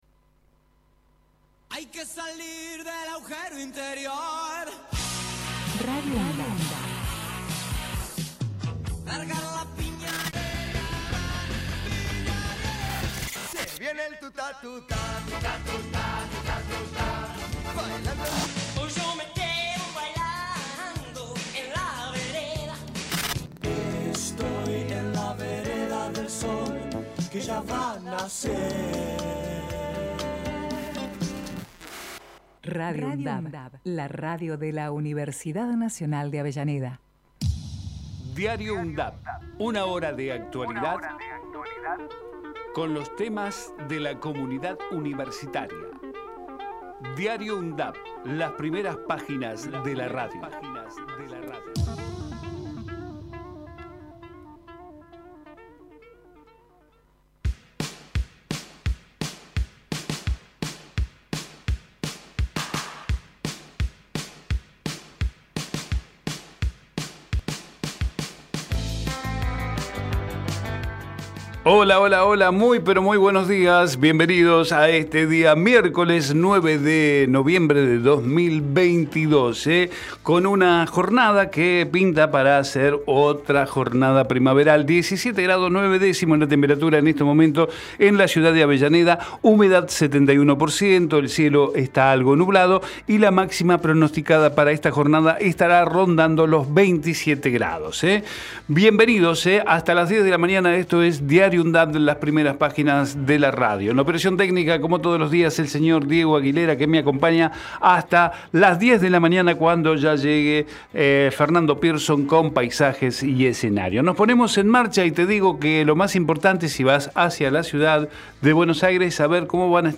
Diario UNDAV Texto de la nota: De lunes a viernes de 9 a 10 realizamos un repaso por la actualidad universitaria en las voces de los protagonistas, testimonios de quienes forman parte de la UNDAV. Investigamos la historia de las Universidades Nacionales de todo el país y compartimos entrevistas realizadas a referentes sociales, culturales y académicos. Todos los días, cerramos Diario UNDAV, con nuestro ciclo de efemérides musicales, histórico y variado.